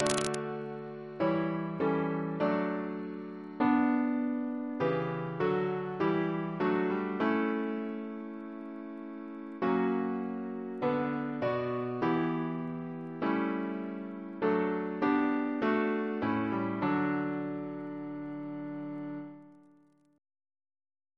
Double chant in D Composer: John Davy (1763-1824), Composer of songs Reference psalters: ACB: 7; ACP: 101; OCB: 67; PP/SNCB: 16; RSCM: 40